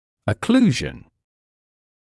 [ə’kluːʒn][э’клю:жн]окклюзия, смыкание зубов; закупорка, непроходимость